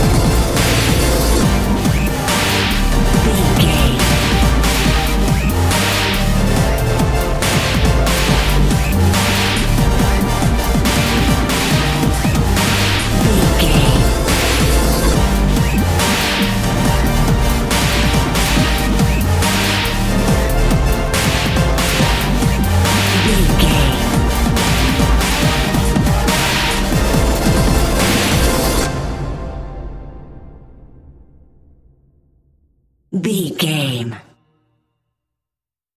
Epic / Action
In-crescendo
Aeolian/Minor
strings
drum machine
synthesiser
synth effects
driving drum beat